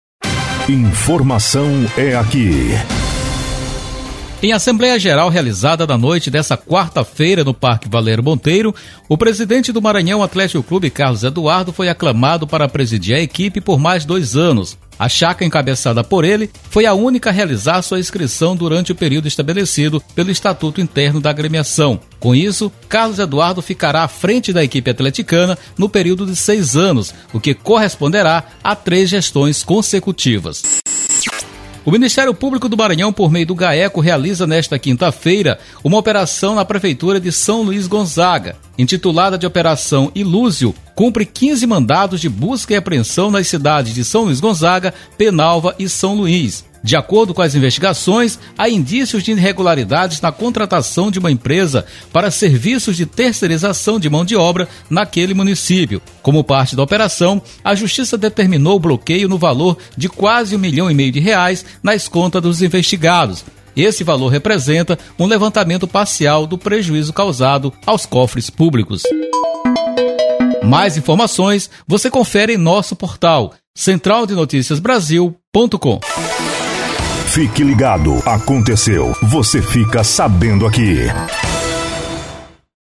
Todos os Plantões de Notícias
Repórter